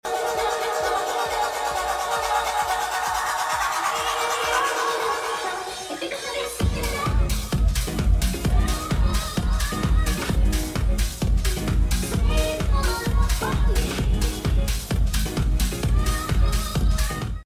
house
edm
housemusic